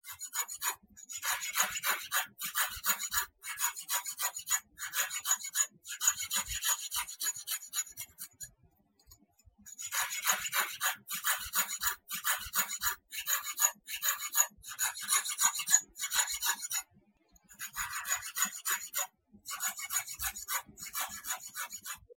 Tiếng thợ Cưa ốc làm Khảm trai, Khảm ốc… ở làng nghề
Thể loại: Tiếng động
Description: Tiếng thợ cưa ốc, mài trai, khảm vỏ óc len lỏi khắp làng nghề như bản nhạc của sự tỉ mỉ và khéo léo. Âm thanh “rẹt rẹt”, “xoẹt xoẹt” vang lên hòa cùng tiếng đục, tiếng giũa, tạo nên giai điệu lao động đặc trưng.
tieng-tho-cua-oc-lam-kham-trai-kham-oc-o-lang-nghe-www_tiengdong_com.mp3